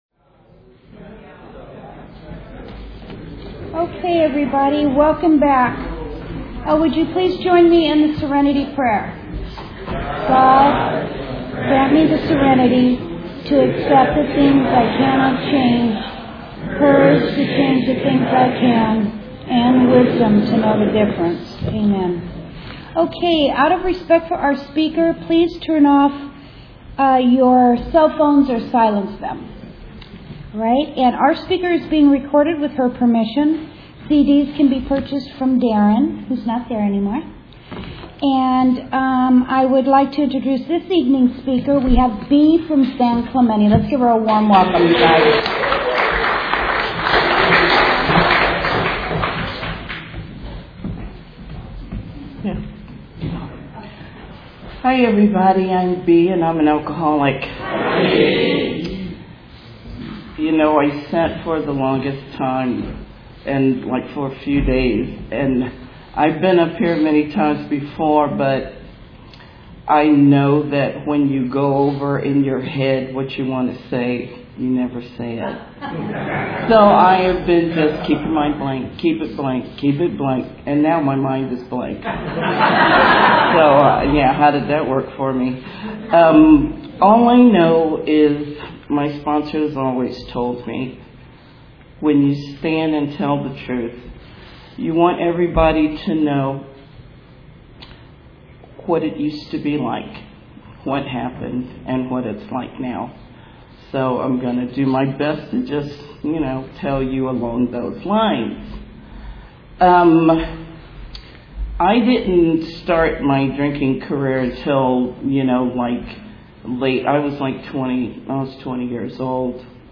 Speaker Tape